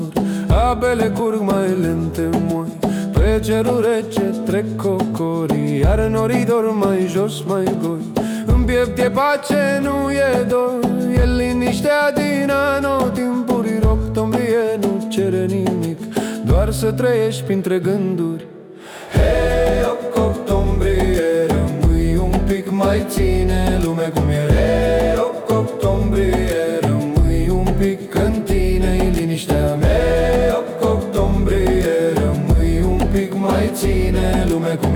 Акустические струны и тёплый вокал
Afro-Beat African Folk
Жанр: Фолк